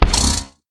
骷髅马：受伤
骷髅马在受伤时随机播放这些音效
Minecraft_skeleton_horse_hurt3.mp3